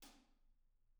R_B Hi-Hat 03 - Room.wav